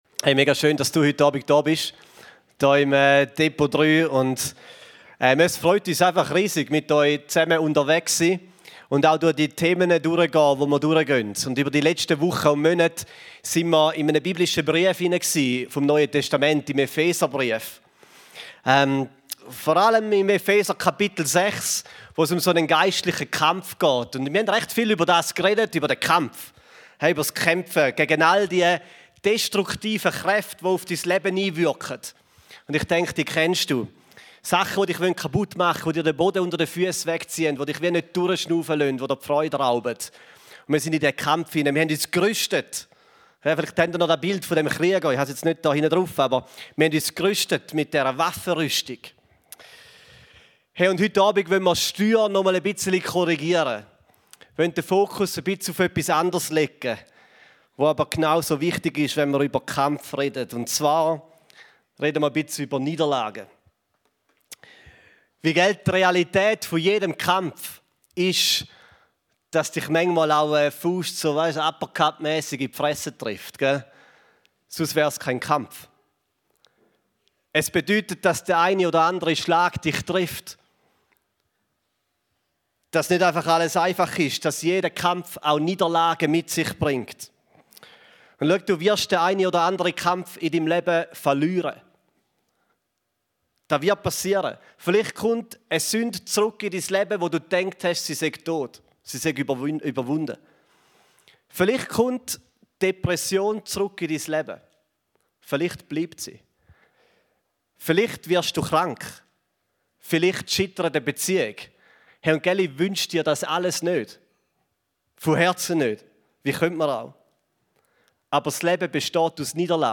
Beschreibung vor 4 Monaten Die Abschluss-Predigt einer Serie über den "Kampf" nimmt eine neue Perspektive ein und spricht offen über die Niederlagen im Leben, die jeder Kampf mit sich bringt.